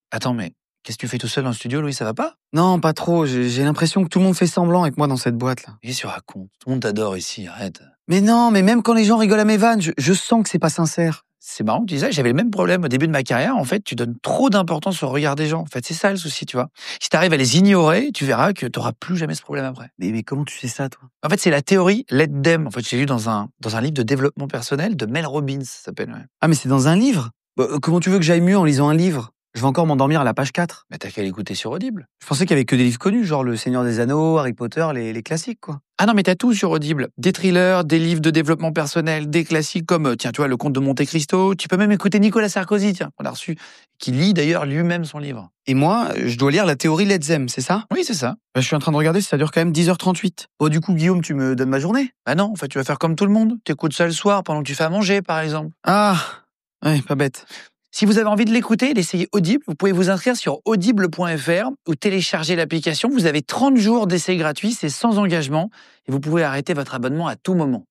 Le volet digital s’appuie sur un format host-read : le podcasteur présente lui-même son coup de cœur en pré-roll sur ses épisodes.
Chaque mois, l’un des deux podcasteurs sélectionne un livre audio et l’introduit par un message personnel, d’environ 45″, avant le contenu principal de son émission.